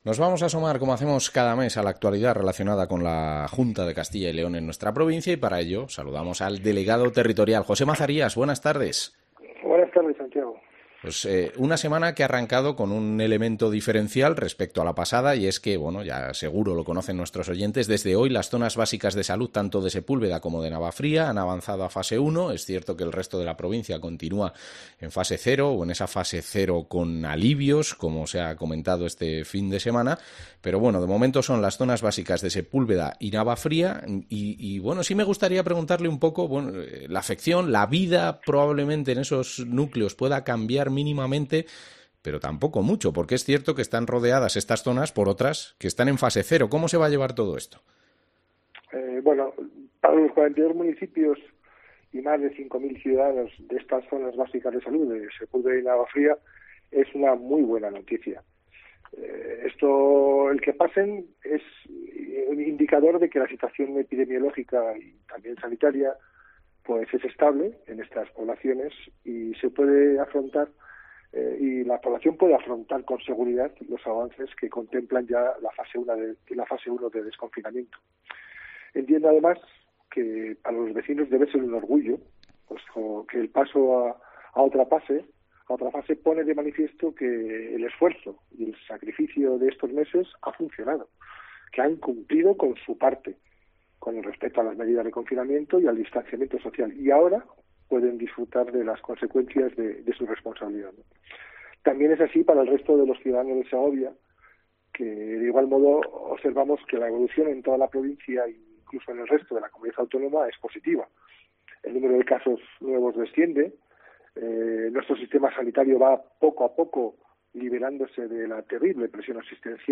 Entrevista al delegado territorial de la Junta de Castilla y León, José Mazarías